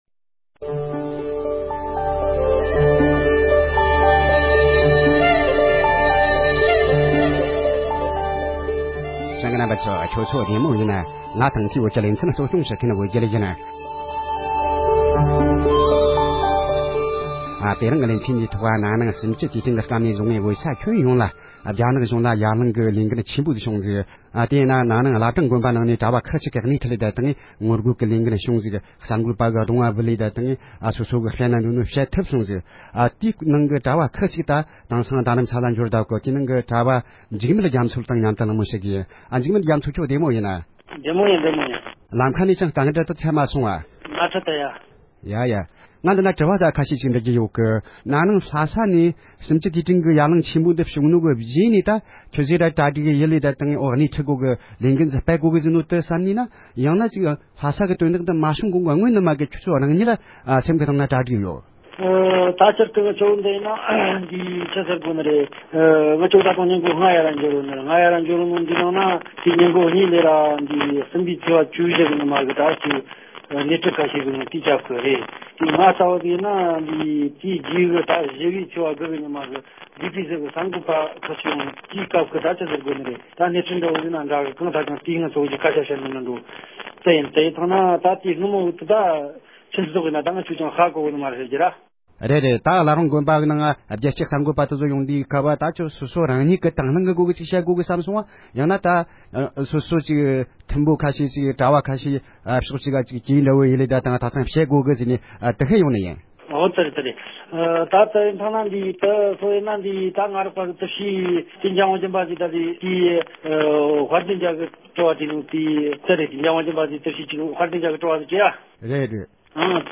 སྒྲ་ལྡན་གསར་འགྱུར།
གླེང་མོལ་ཞུས་པ་ཞིག